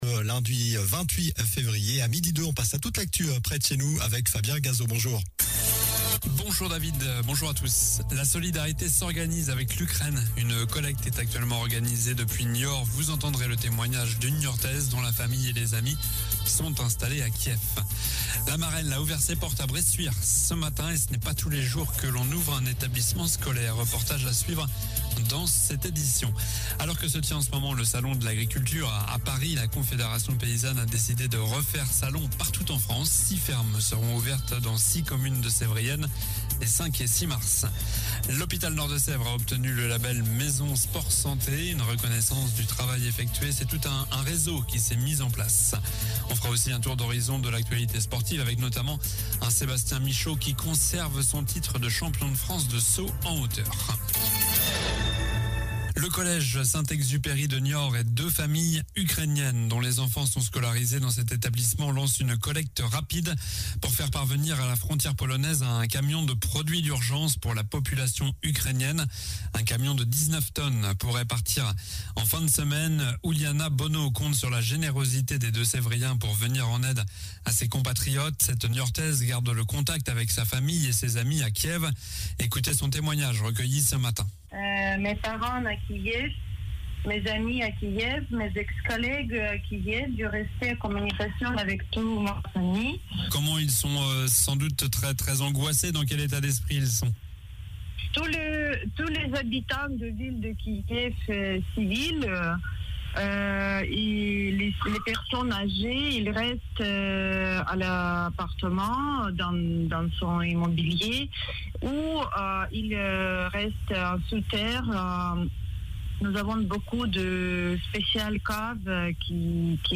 Journal du lundi 28 février (midi)